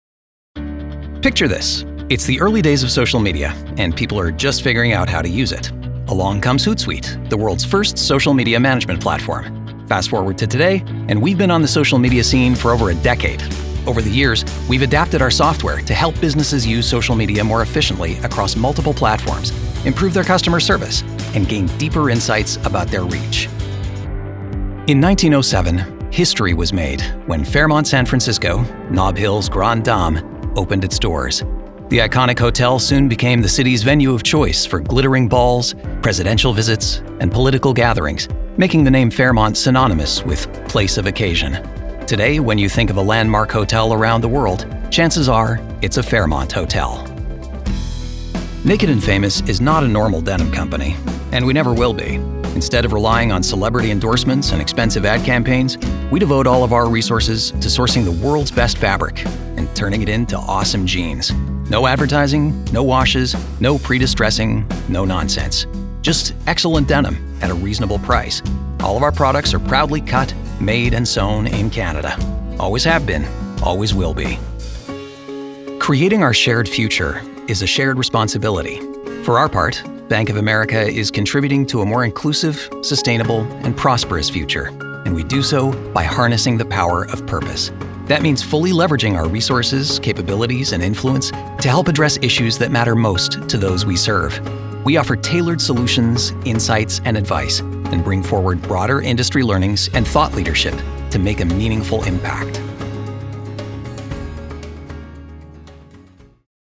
Male
American English (Native) , Canadian English (Native) , French Canadian
Approachable, Assured, Authoritative, Confident, Conversational, Corporate, Deep, Energetic, Engaging, Friendly, Funny, Gravitas, Natural, Posh, Reassuring, Sarcastic, Smooth, Soft, Upbeat, Versatile, Warm, Witty
Microphone: Sennheiser 416